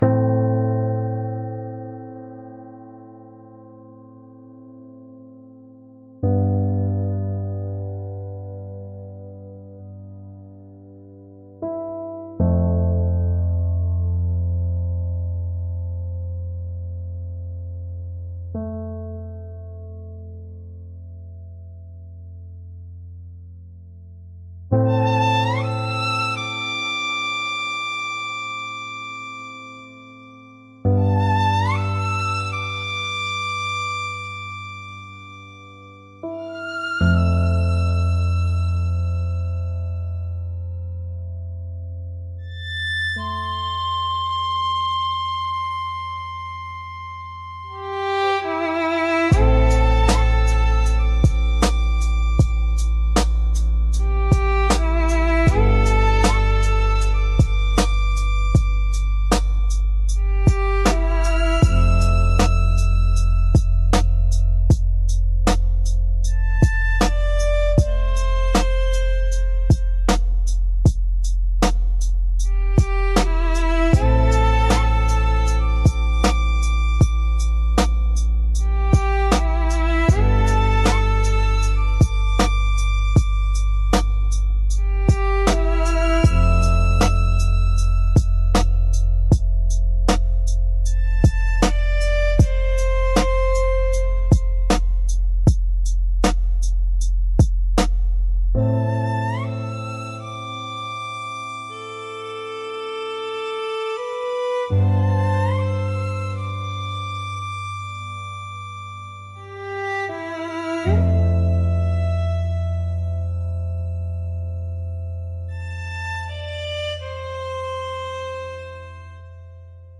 F Major – 78 BPM
Electronic
Sad